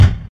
Smooth Kick Drum Single Hit G Key 725.wav
Royality free kick tuned to the G note. Loudest frequency: 286Hz
smooth-kick-drum-single-hit-g-key-725-XND.mp3